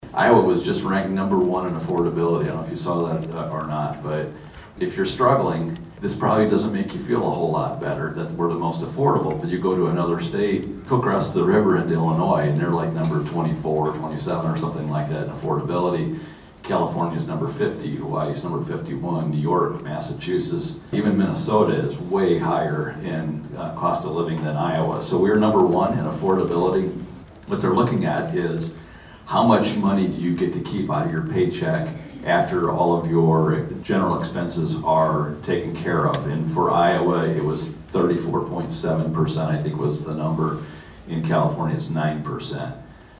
The Carroll Chamber of Commerce and Carroll County Growth Partnership (CCGP) hosted its third legislative forum of the 2026 session on Saturday, and the future of Iowa’s workforce was one of the questions brought to District 6 Sen. Jason Schultz (R-Schleswig) and District 11 Rep. Craig Williams (R-Manning).